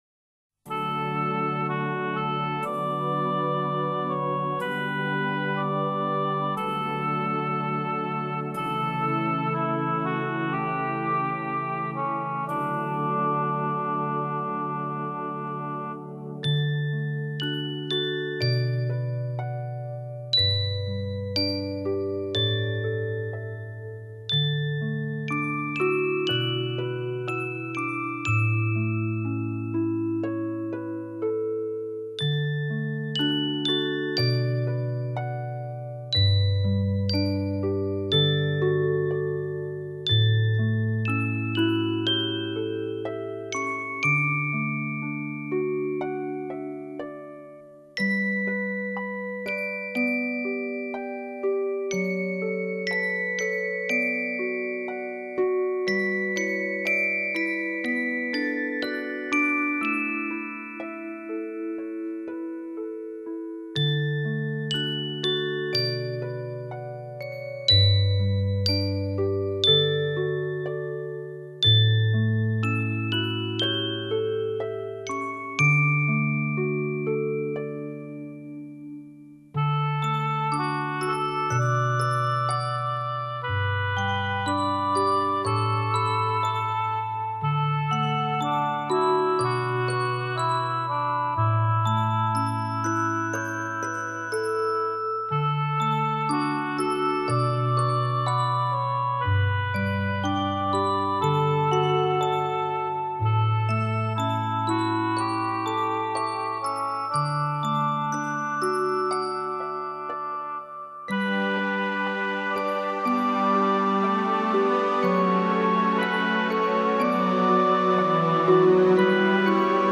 这是本人收集的水晶琴佛曲，非常好听。